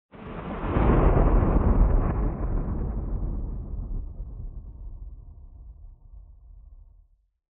thunder_far.ogg